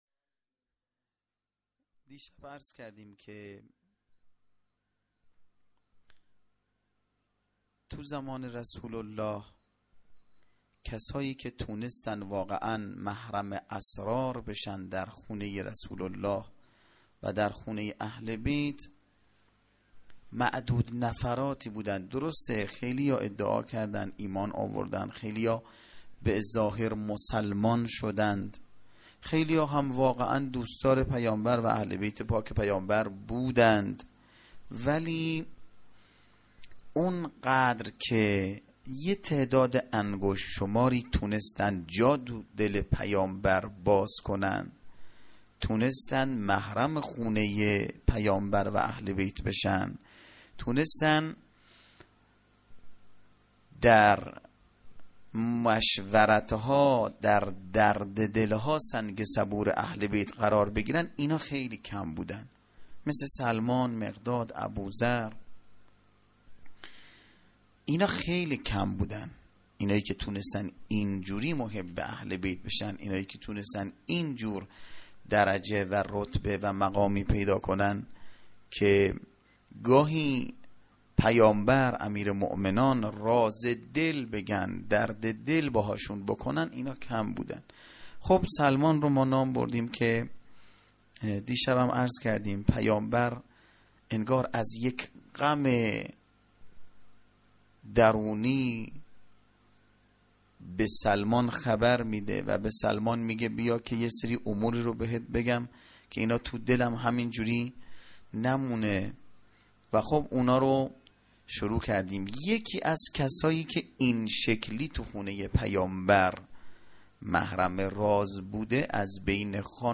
sokhanrani.mp3